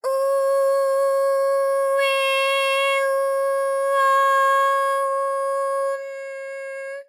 ALYS-DB-001-JPN - First Japanese UTAU vocal library of ALYS.
u_u_e_u_o_u_n.wav